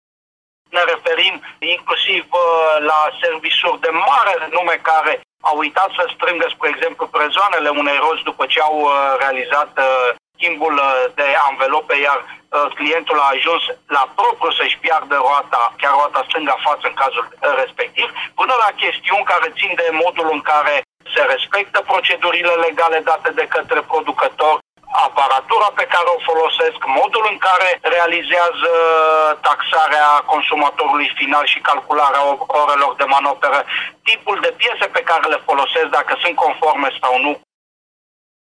Atât au strâns din amenzi comisarii de la Protecția Consumatorului Brașov, după neregulile grave constatate în service-urile auto și în magazinele de piese pentru mașini. Șeful CRPC Brașov, Sorin Susanu: